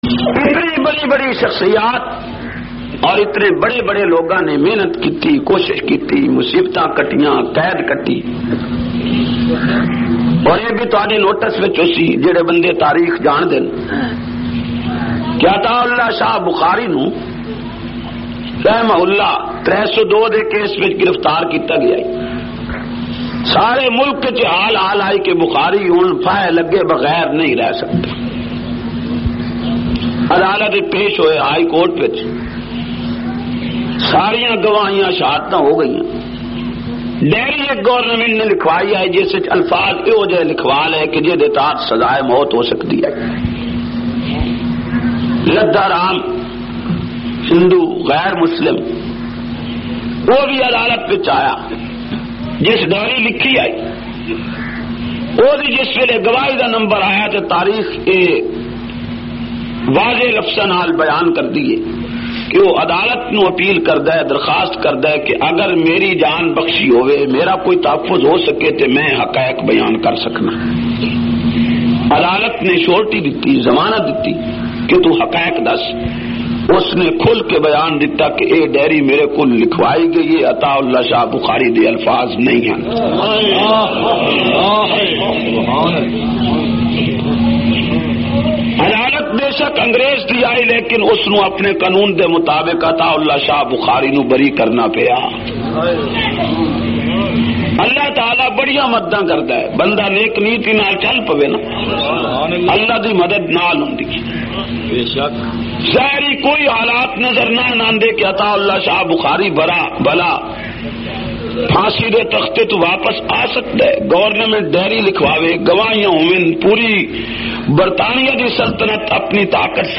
244- Shan e Usman e Ghani Punjabi Bayan Rashidpur 18 Hazari.mp3